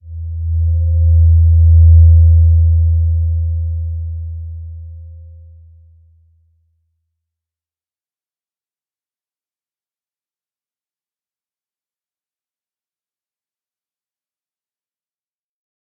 Slow-Distant-Chime-E2-p.wav